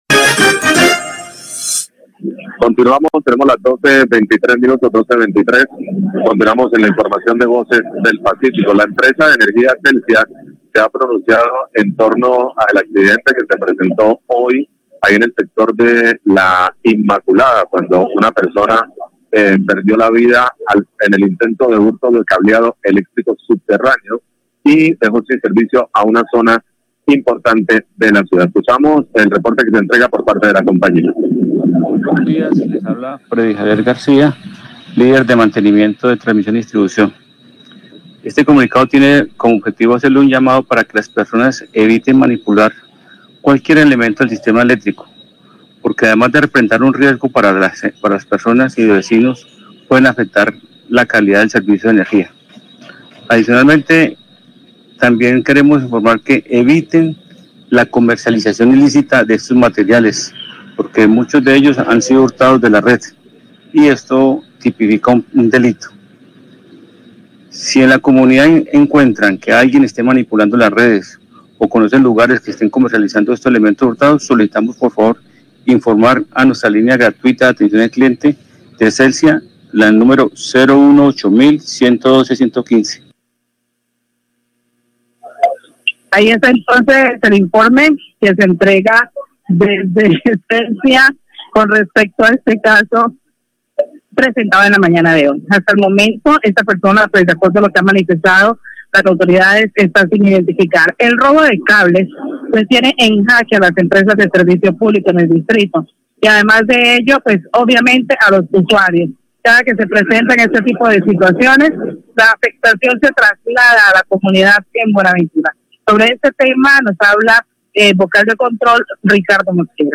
comunicado de prensa